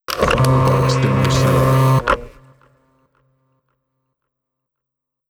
“Voltrax” Clamor Sound Effect
Can also be used as a car sound and works as a Tesla LockChime sound for the Boombox.